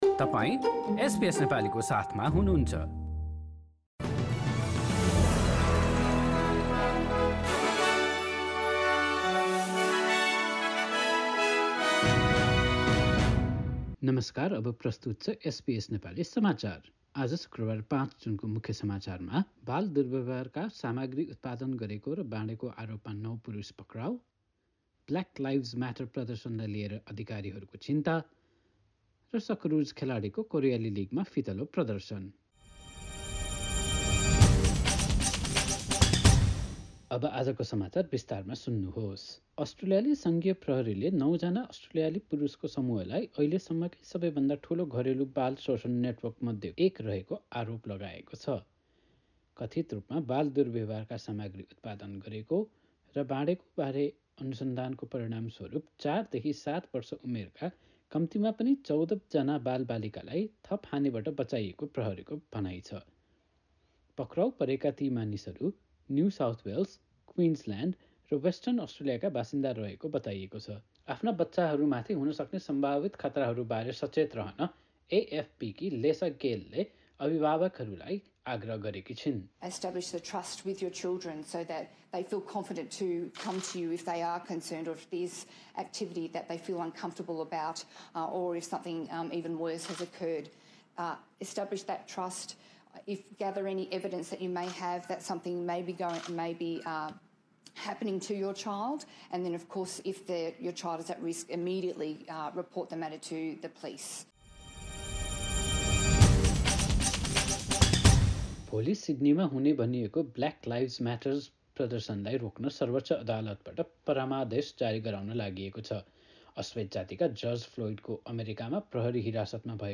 एसबीएस नेपाली अस्ट्रेलिया समाचार: शुक्रवार ५ जुन २०२०